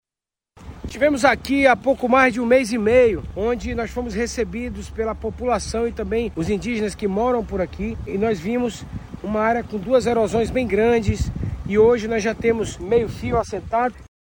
Sonora-1-Renato-Junior-–-secretario-da-Seminf.mp3